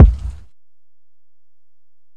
Kick (37).wav